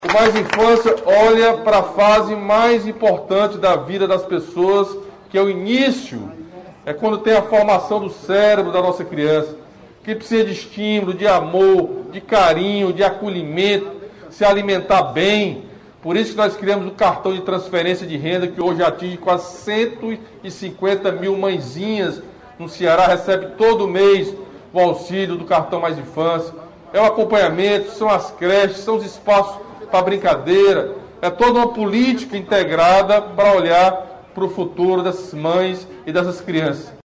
O governador Camilo Santana destacou também outras ações executadas no Ceará pelo Programa Mais Infância Ceará.